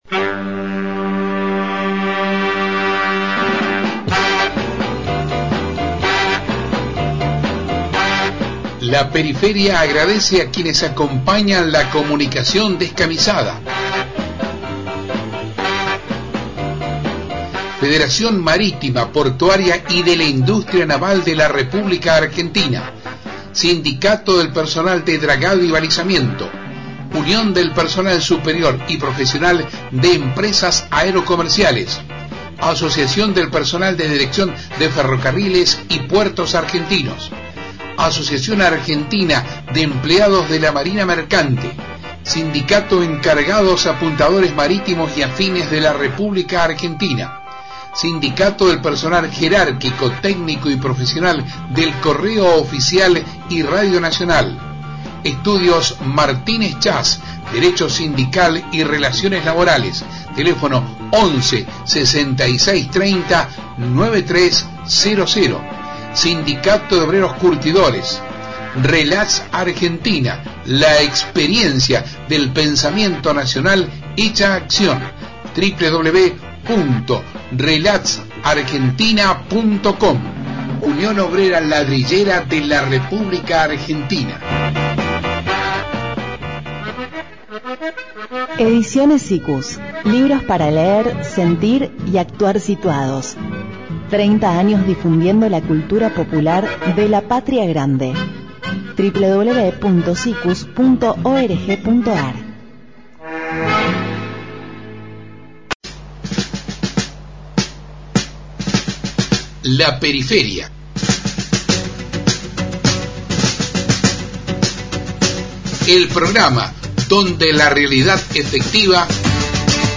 Con el fin de promover el resurgimiento del peronismo se desarrolló el último lunes un encuentro de militancia donde se expuso la necesidad de concretar la unidad peronista en el distrito porteño.